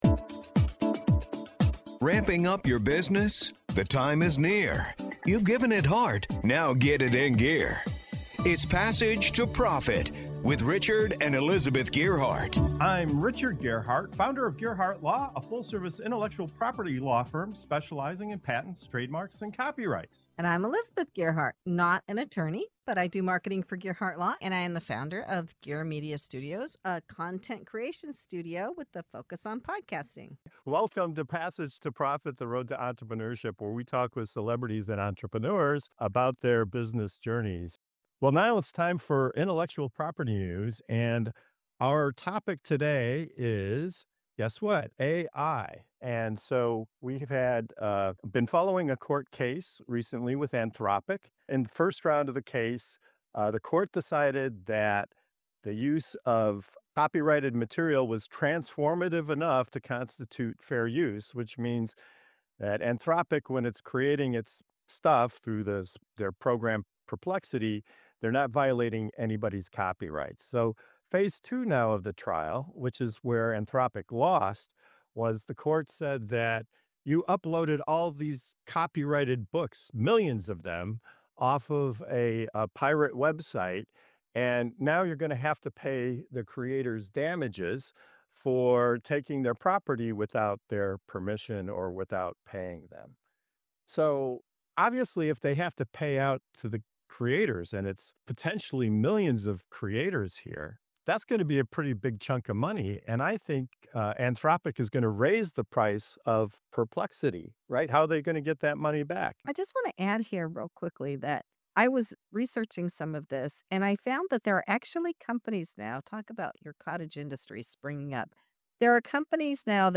Our panel breaks down the latest Anthropic court case — including the surprising decision that uploading pirated books could cost millions in damages — and debates what this could mean for the price of AI tools like Perplexity. We explore whether creators should get paid, how much users might be willing to pay, and what this all means for businesses relying on AI to save time and money. It’s a lively, practical, and eye-opening conversation about the real cost of artificial intelligence.